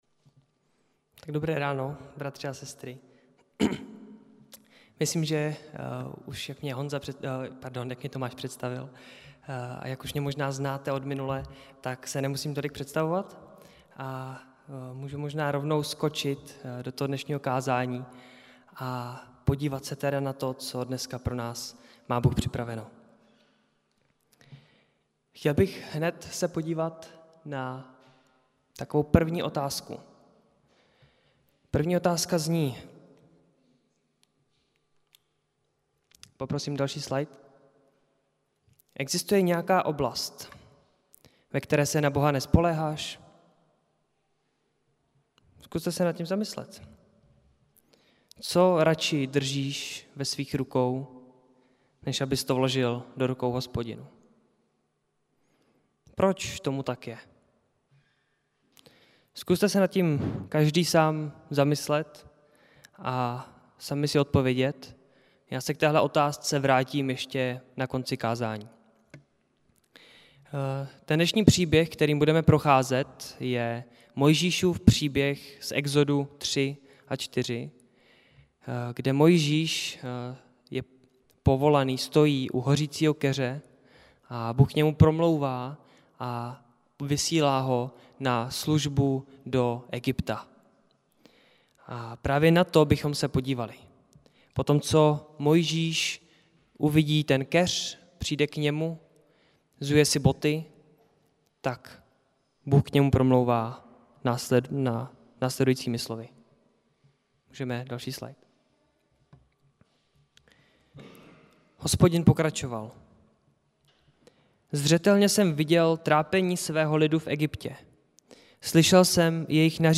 Nedělní kázání 27.9.2020